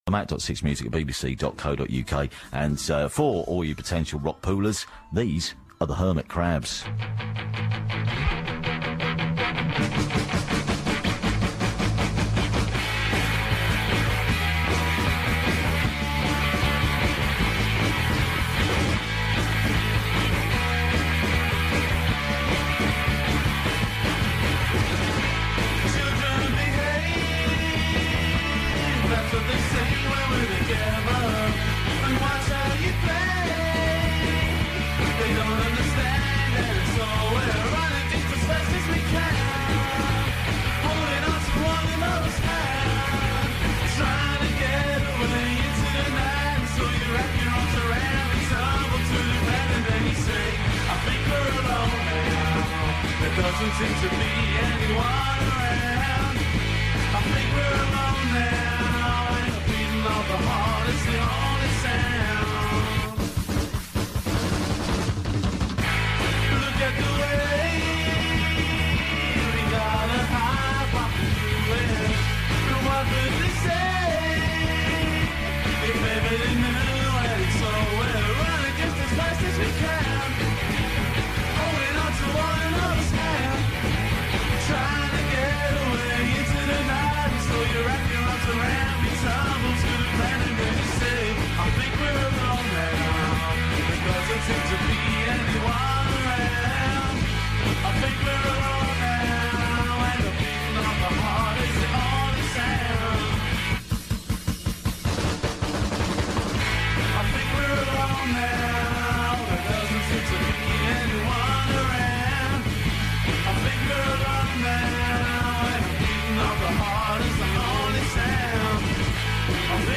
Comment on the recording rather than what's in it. (taken from the radio)